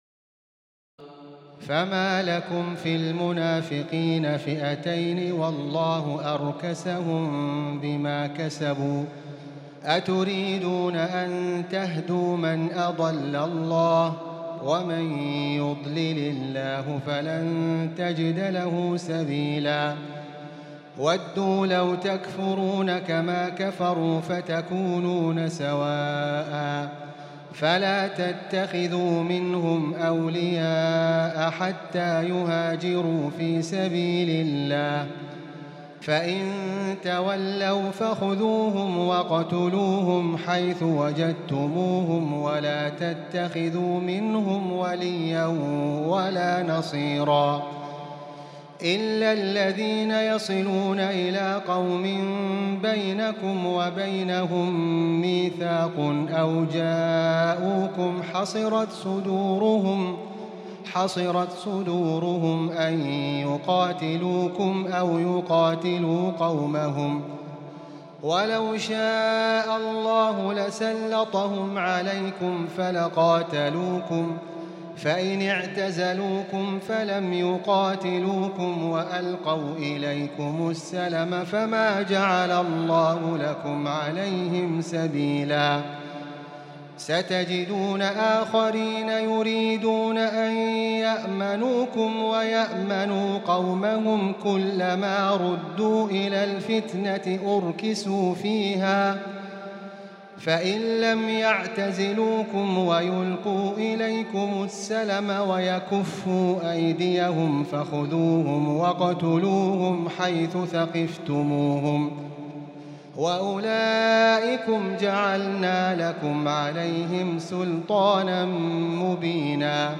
تراويح الليلة الخامسة رمضان 1438هـ من سورة النساء (88-162) Taraweeh 5 st night Ramadan 1438H from Surah An-Nisaa > تراويح الحرم المكي عام 1438 🕋 > التراويح - تلاوات الحرمين